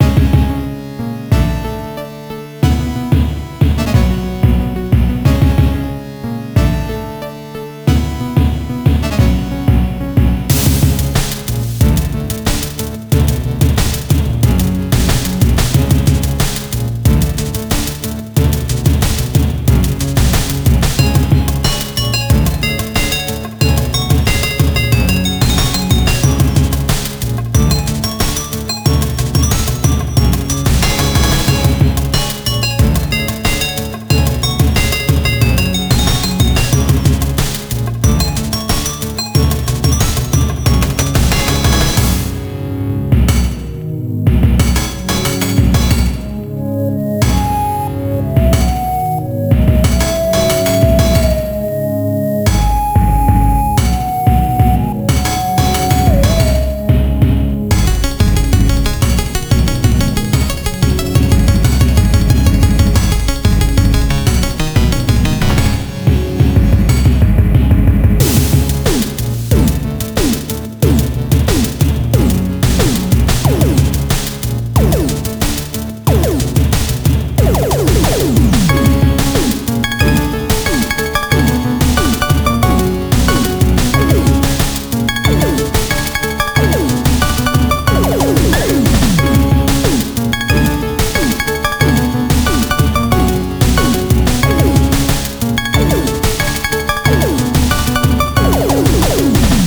A song, more SNES goodness... very short tho.